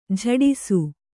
♪ jhaḍisu